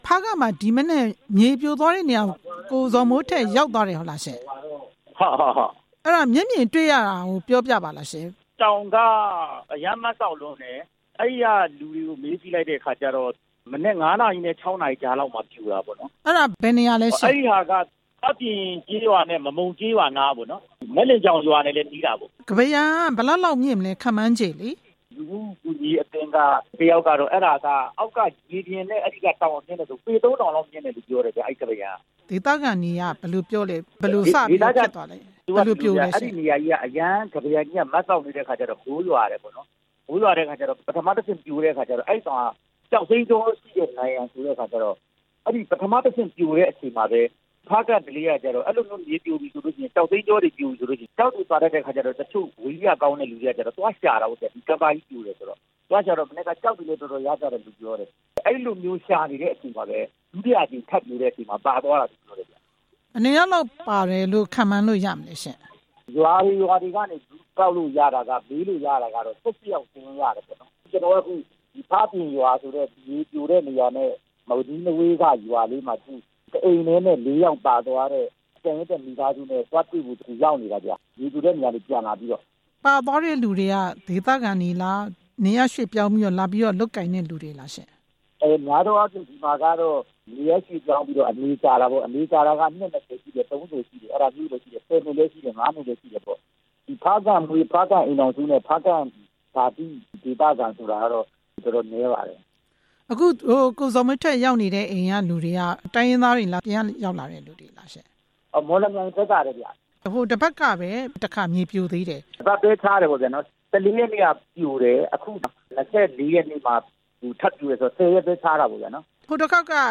ဖားကန့်မြေပြိုကျမှု ဆက်သွယ်မေးမြန်းချက်